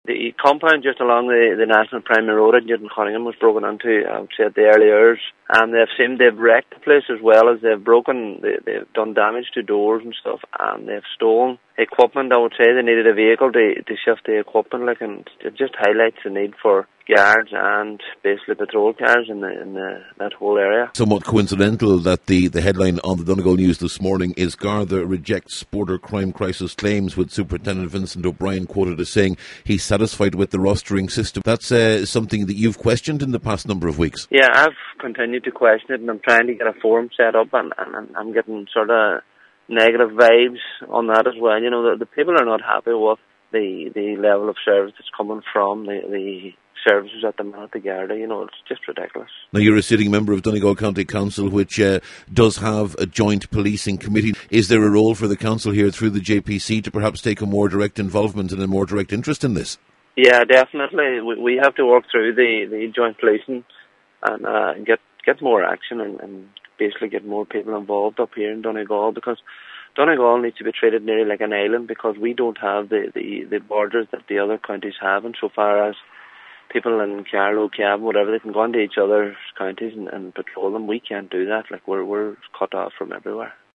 He’s been speaking about the latest incident in Newtowncunningham……….